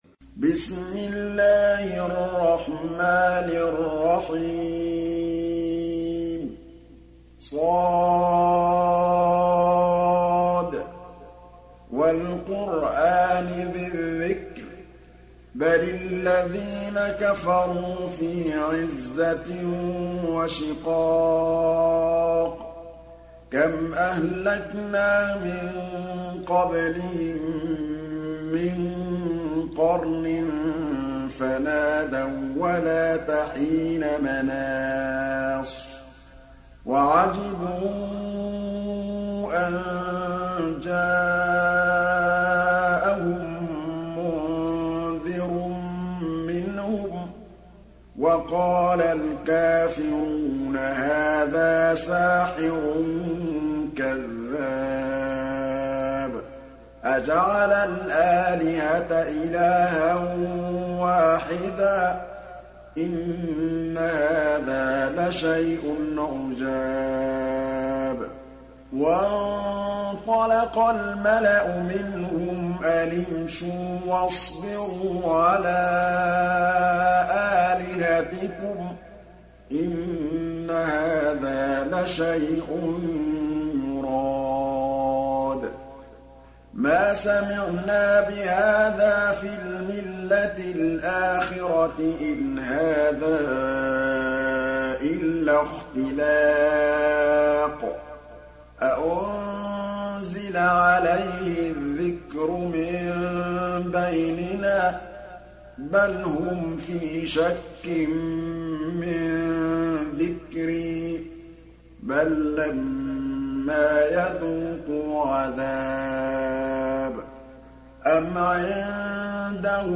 Moratal